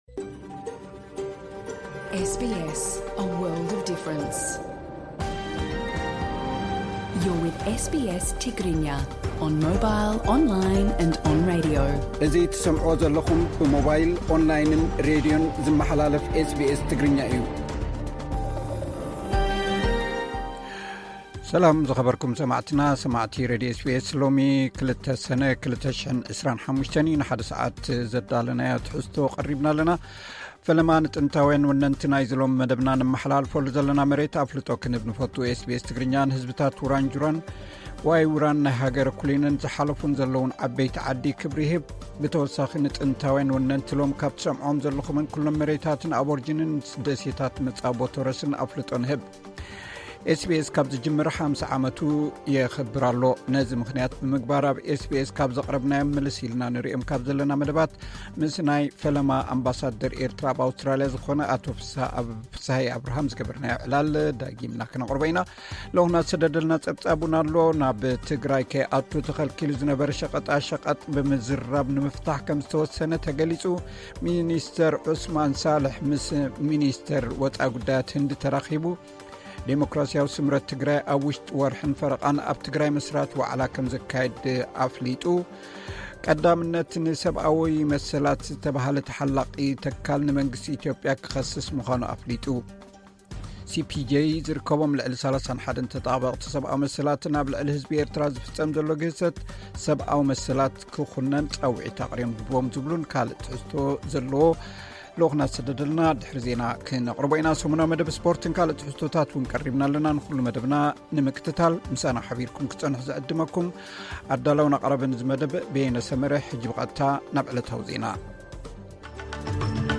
ዕለታዊ ዜና ኤስ ቢ ኤስ ትግርኛ (02 ሰነ 2025)